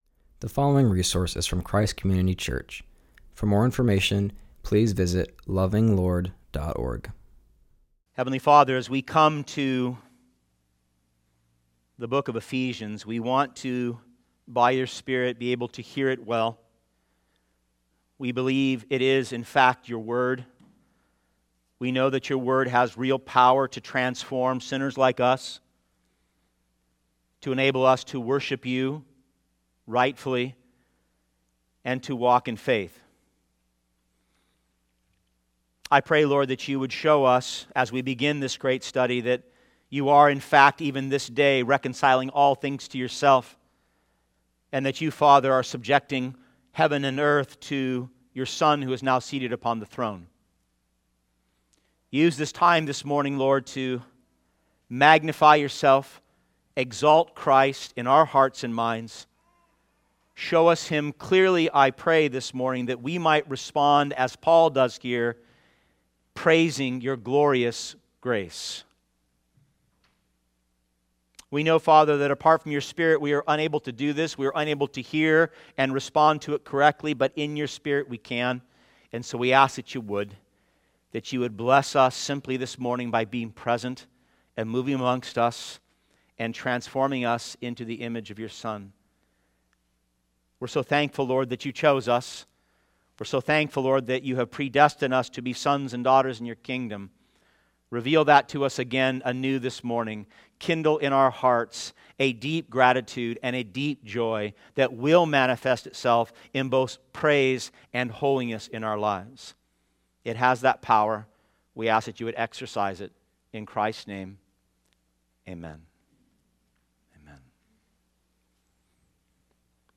starts a new series in Ephesians and preaches from Ephesians 1:1-6.